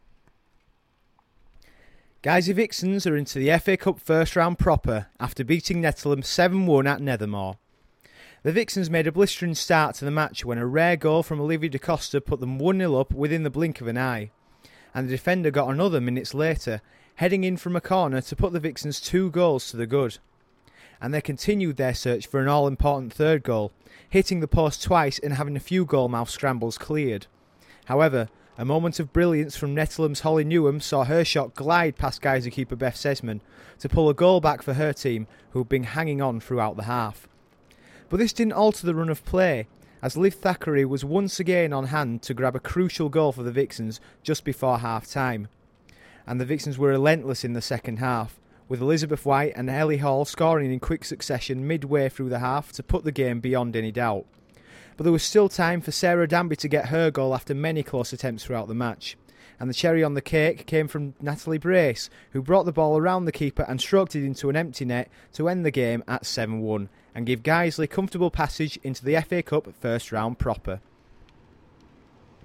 Vixens v Nettleham match report